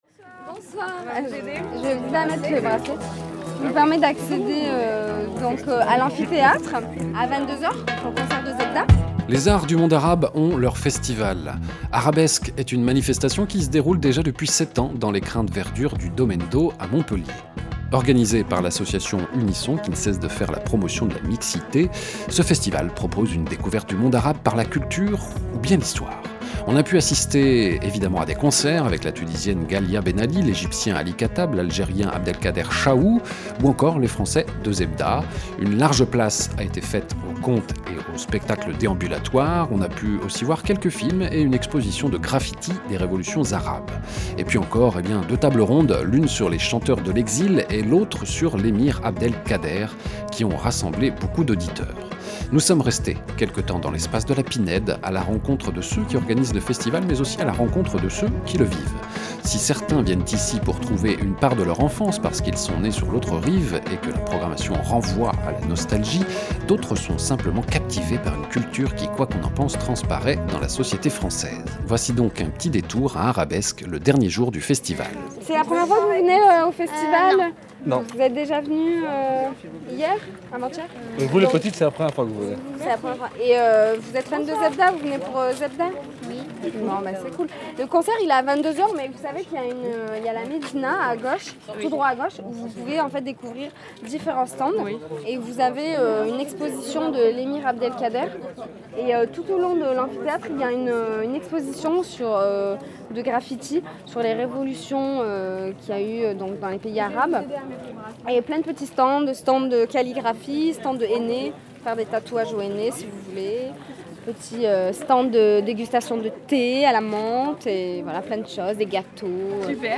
epra -  divfm - arabesques - reportage d'ambiance.mp3